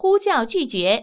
ivr-call_rejected.wav